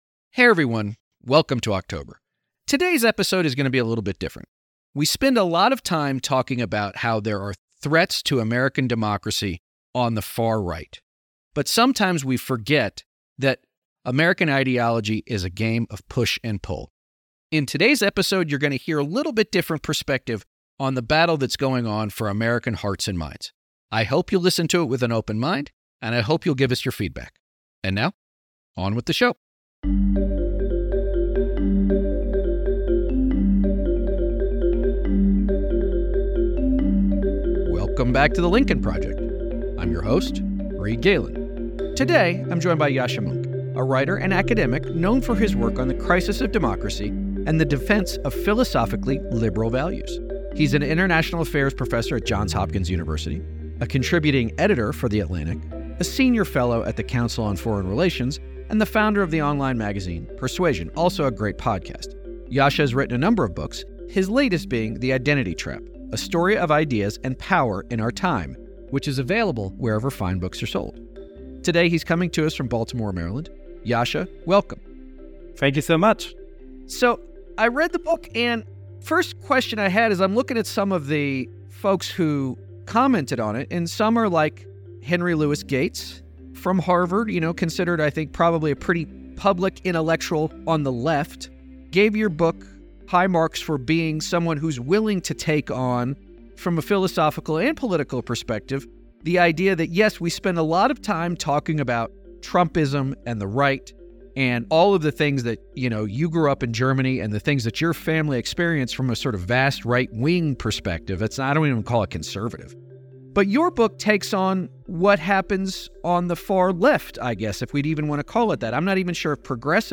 a wide-ranging and nuanced discussion on American identity and how it informs our society, our political discourse, and ultimately our small-d democratic future. Plus, what to expect in the upcoming Polish election.